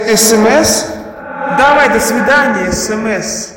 • Качество: 320, Stereo
короткие
на смс
голосовые